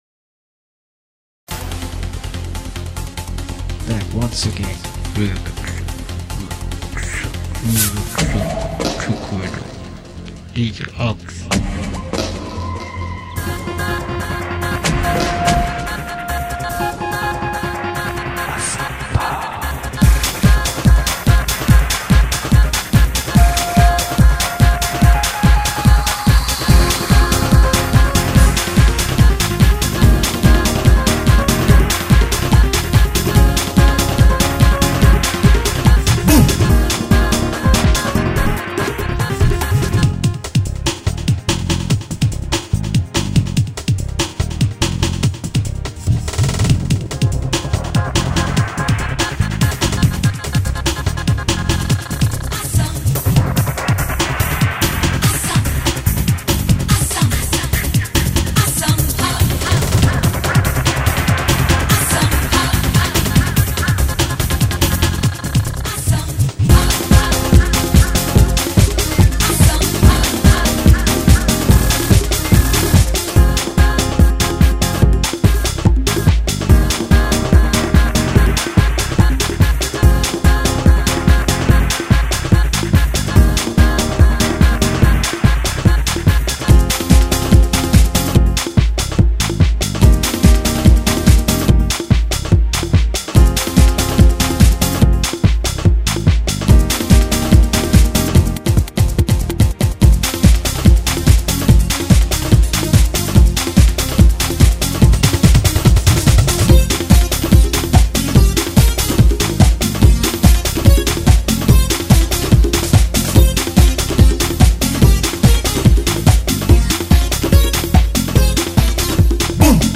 dance/electronic
Industrial